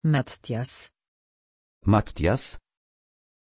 Pronunciación de Mattias
pronunciation-mattias-pl.mp3